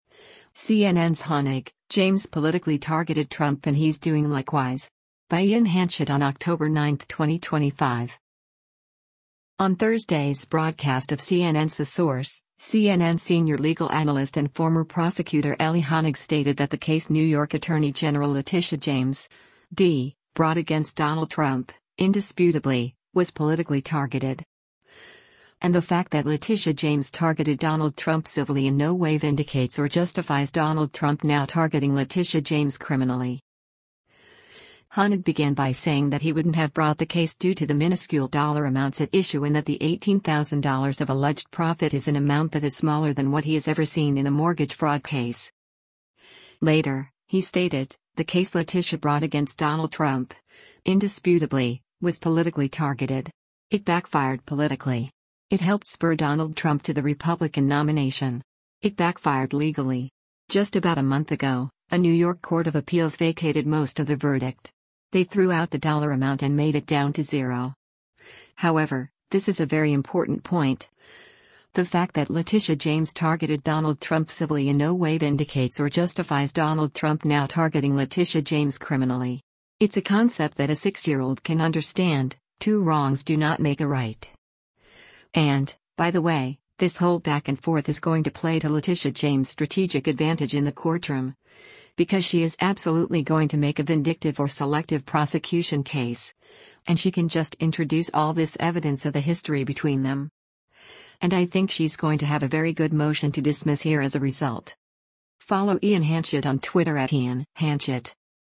On Thursday’s broadcast of CNN’s “The Source,” CNN Senior Legal Analyst and former prosecutor Elie Honig stated that the case New York Attorney General Letitia James (D) “brought against Donald Trump, indisputably, was politically targeted.”